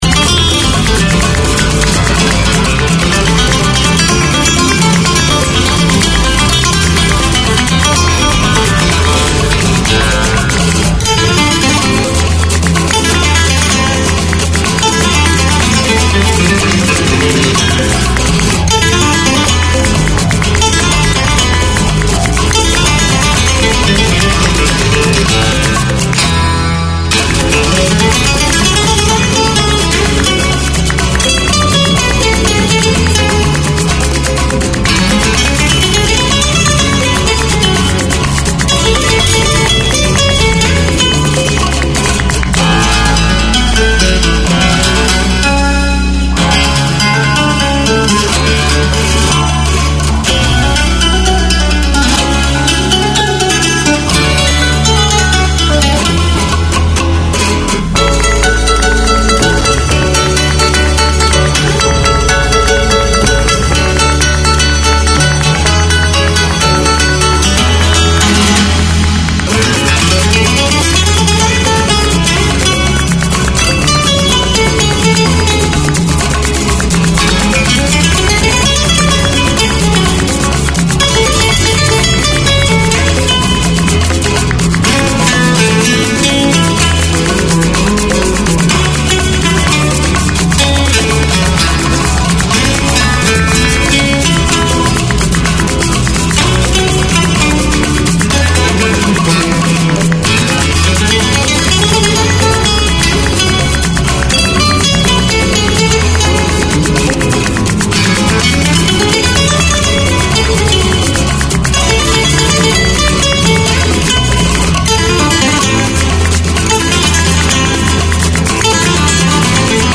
A reflection of the Khmer community in Auckland. A strong news selection including live reports from Cambodia plus music, interviews, religious topics, settlement topics and issues.